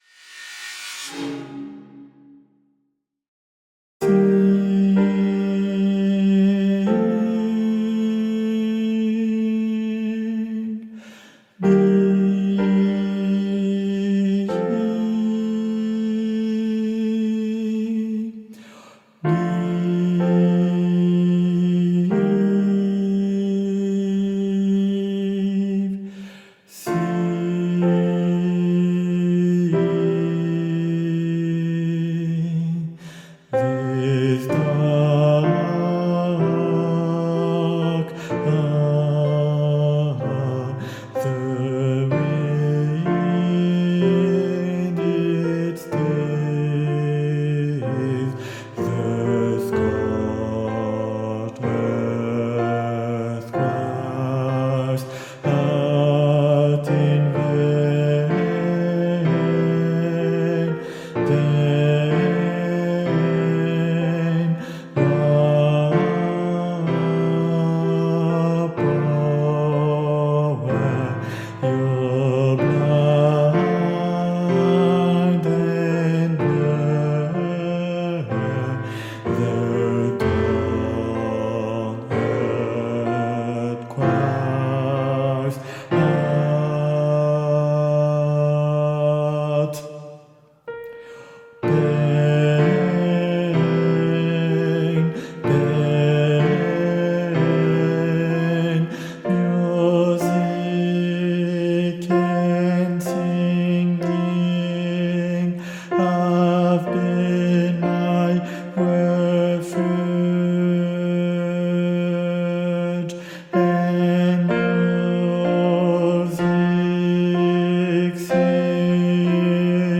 - Chant a capella à 4 voix mixtes SATB
Guide Voix Altos